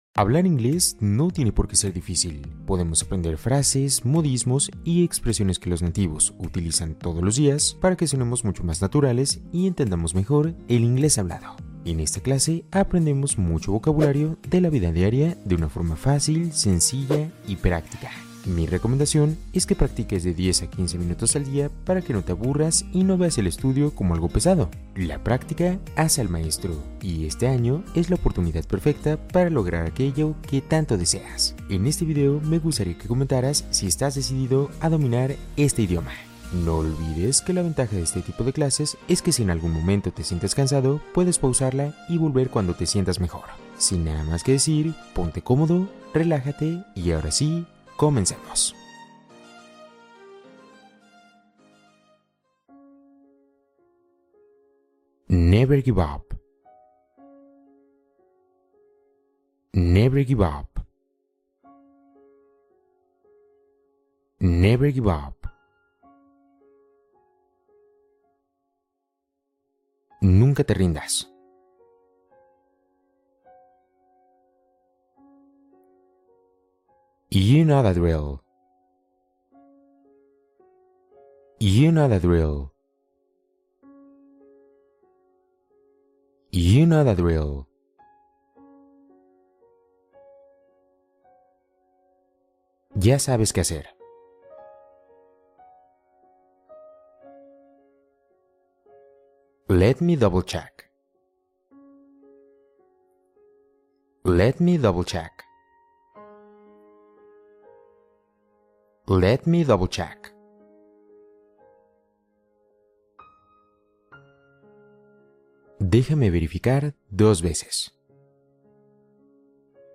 Conversaciones en inglés para mejorar tu comprensión